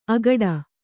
♪ agaḍa